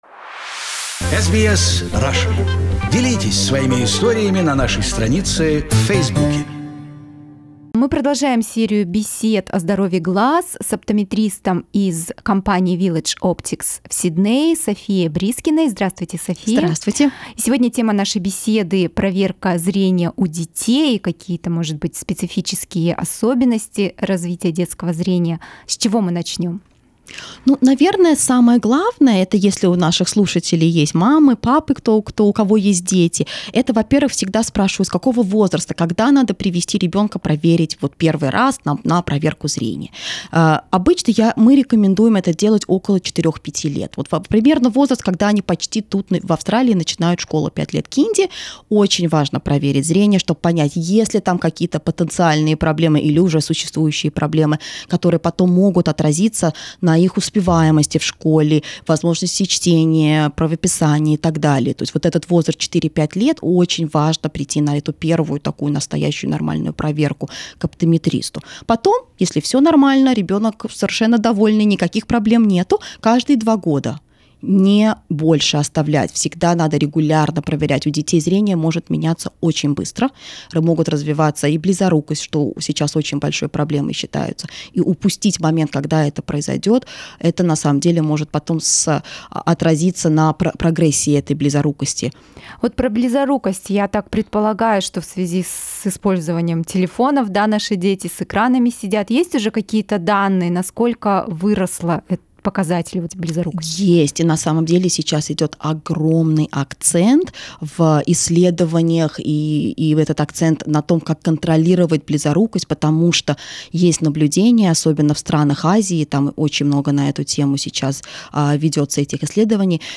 Conversation with an Optometrist: Your Child's Vision